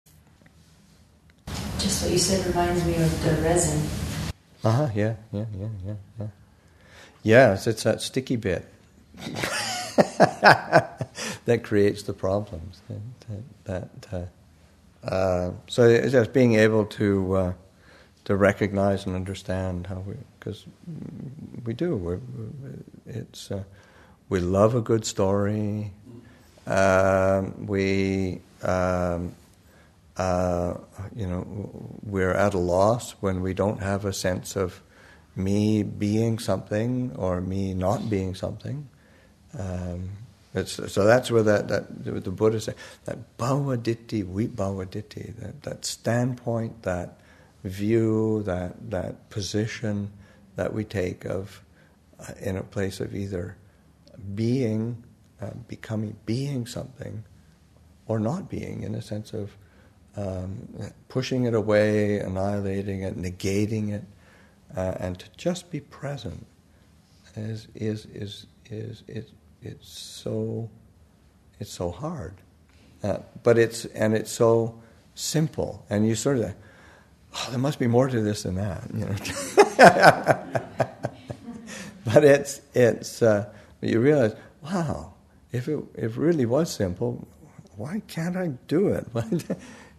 [audio unclear] [Not-self]